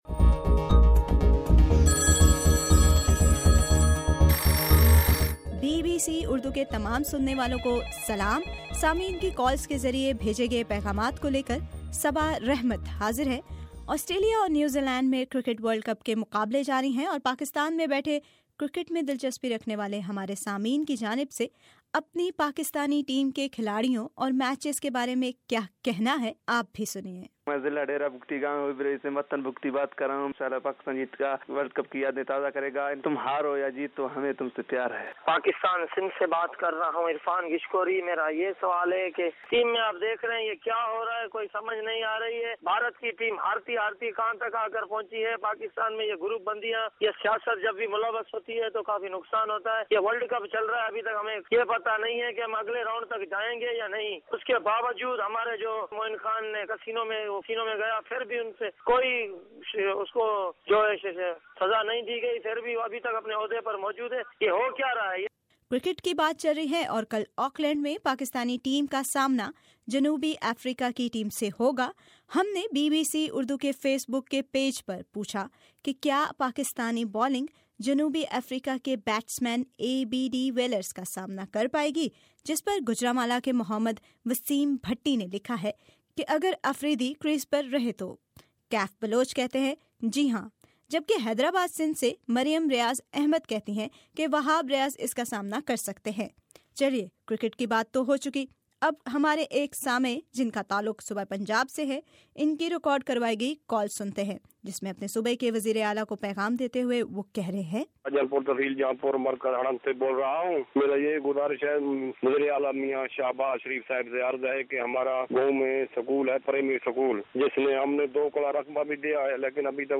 قارئین کی آرا اور سامیعن کی کالز
بی بی سی اردو کو موصول ہونے والے سامیعن کے صوتی پیغامات اور قارئین کی آرا ۔۔۔